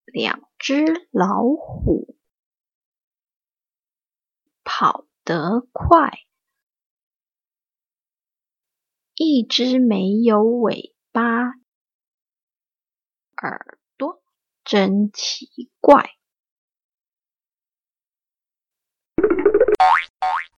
我唸一句，換你唸一句：請家長陪著學童搭配錄音檔一起唸一唸。